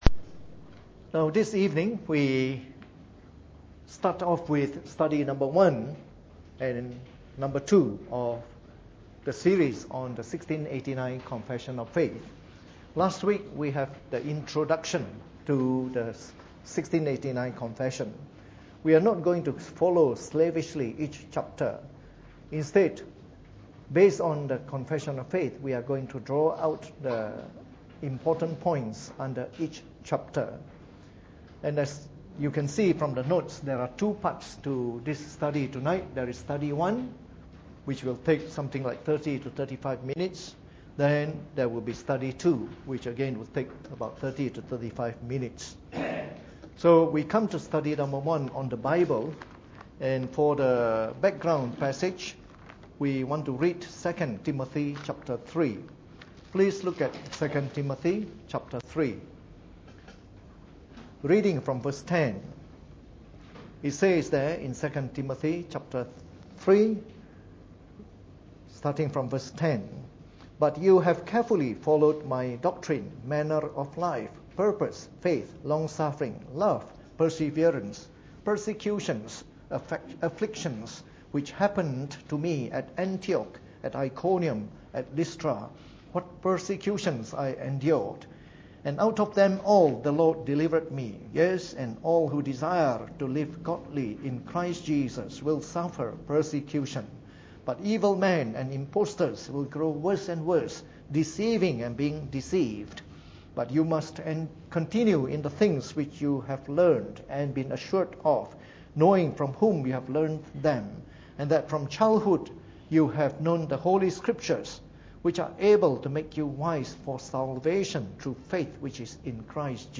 Preached on the 16th of March 2016 during the Bible Study, from our series on the Fundamentals of the Faith (following the 1689 Confession of Faith).